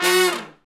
Index of /90_sSampleCDs/Roland L-CDX-03 Disk 2/BRS_R&R Horns/BRS_R&R Falls